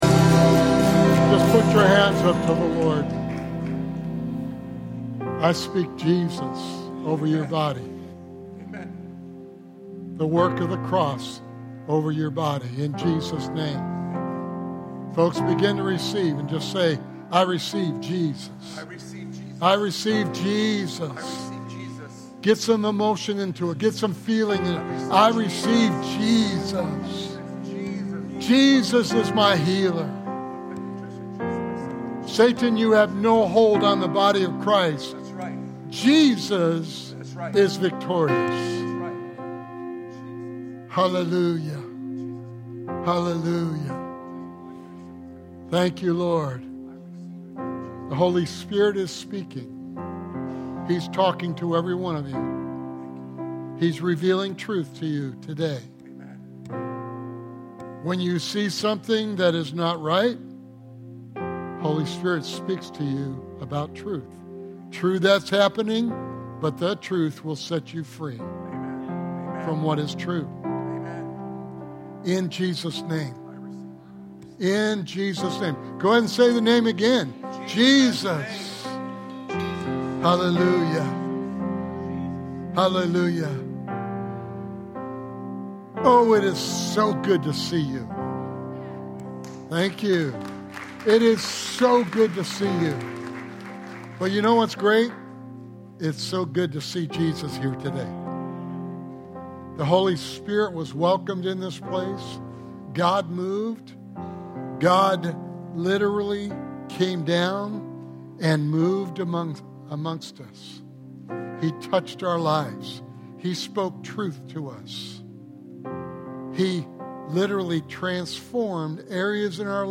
Sermon Series: The God I Never Knew